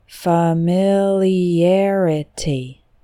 Medium: